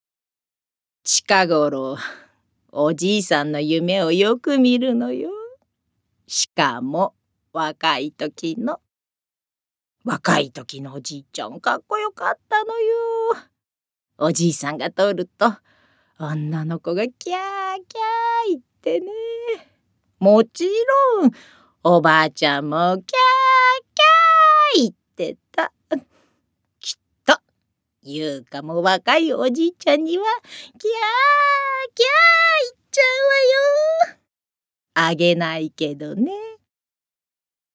ボイスサンプル
セリフ４